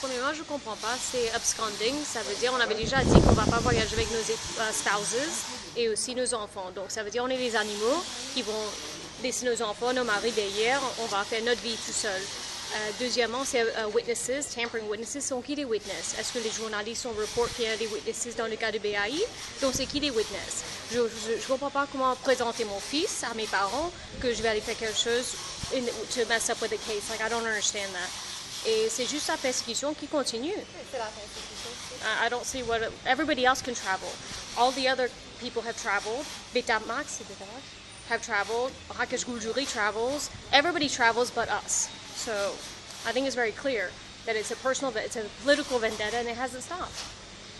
Déclaration